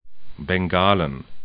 Pronunciation
bɛŋ'ga:lən